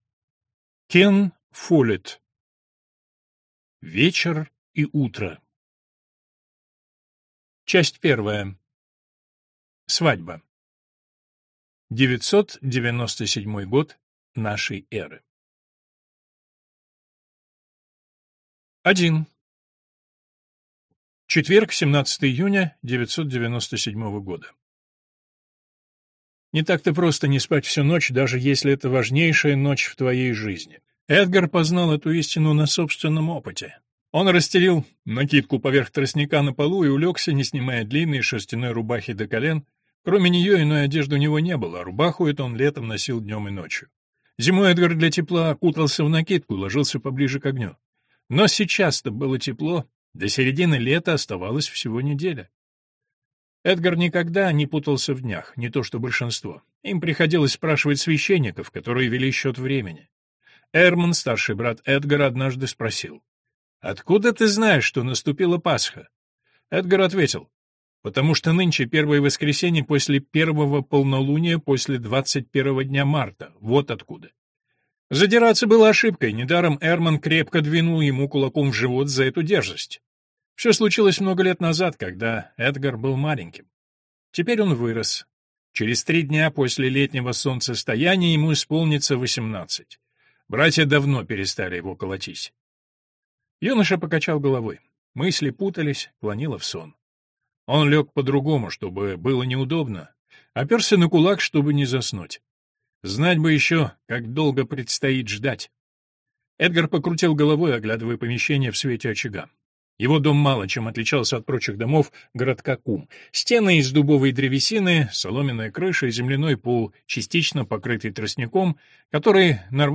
Аудиокнига Вечер и утро (Часть 1) | Библиотека аудиокниг